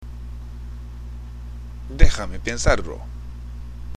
（デハメ　ペンサールロ）